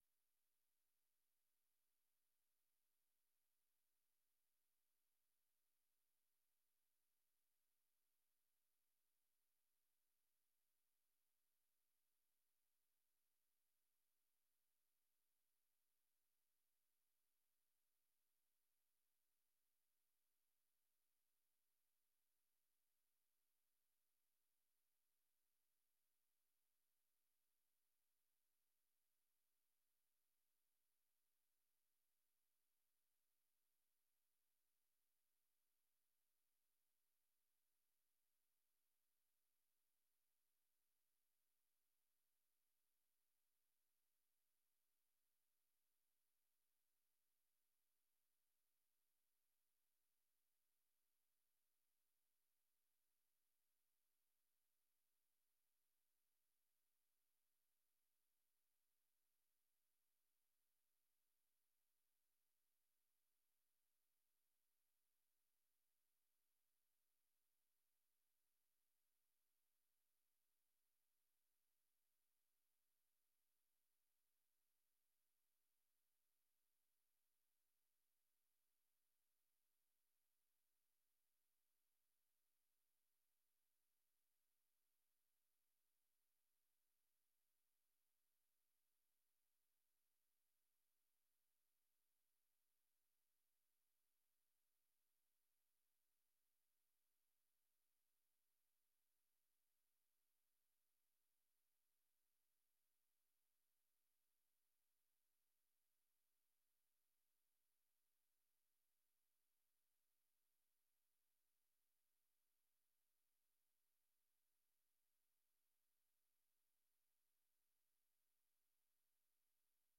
Gemeenteraad 10 november 2025 13:30:00, Gemeente Noordoostpolder
Download de volledige audio van deze vergadering